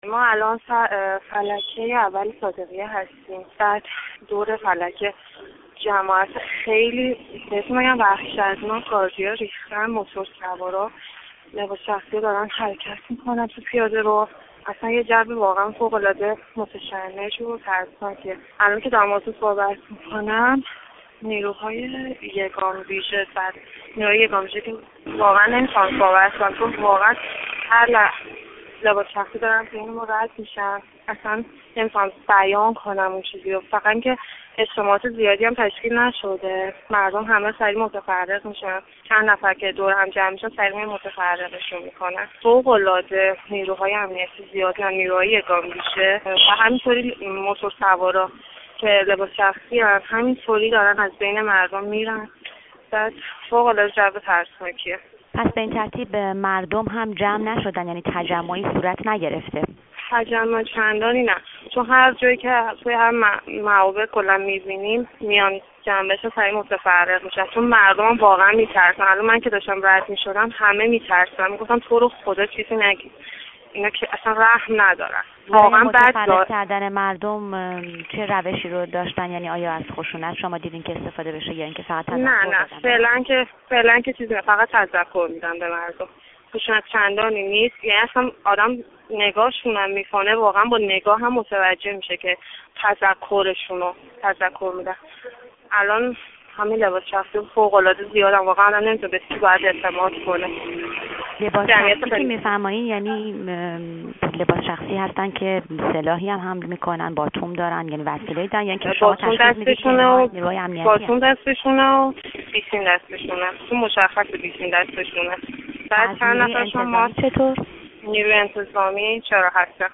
گفت و گوی
با یک شاهد عینی در میدان صادقیه